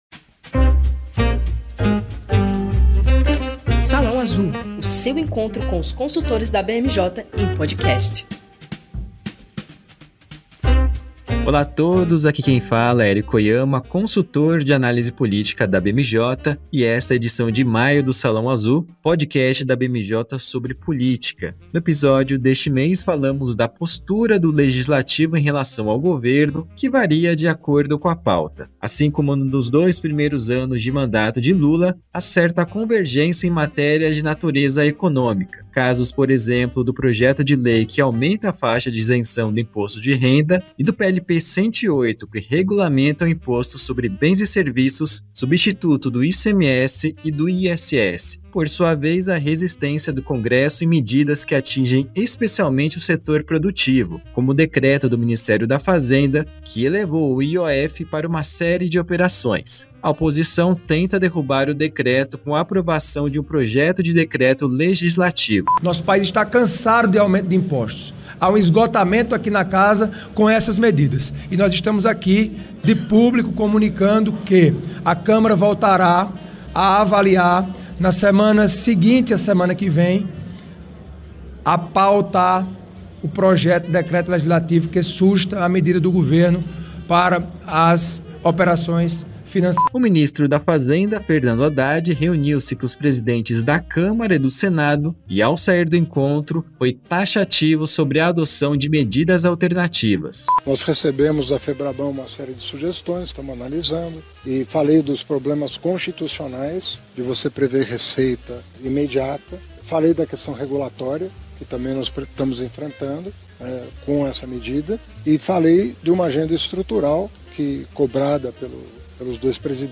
No episódio de maio do podcast Salão Azul, os consultores da BMJ analisam os principais desdobramentos do cenário político nacional. Em pauta, a crise provocada pelo decreto do IOF, os atritos entre o Congresso Nacional e o Executivo, e os bastidores da corrida eleitoral para 2026.